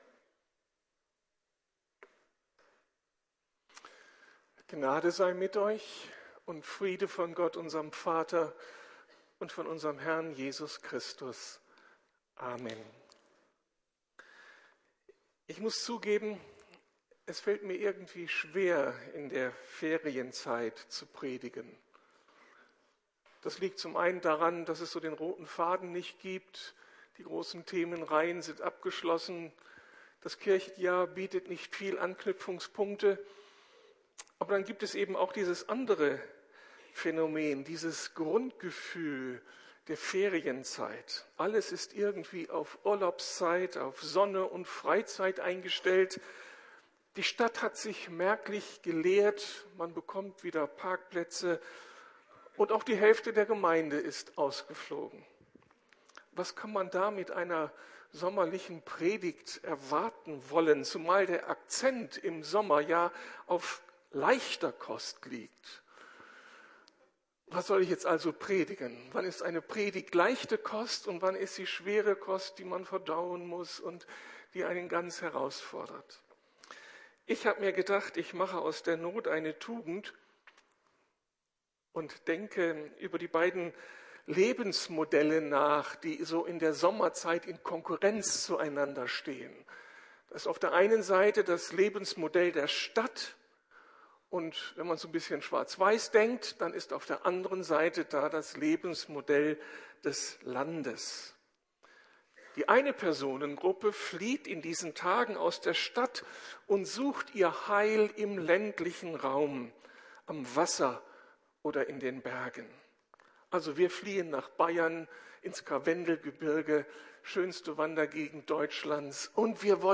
Über Gott staunen - auch in der Stadt! ~ Predigten der LUKAS GEMEINDE Podcast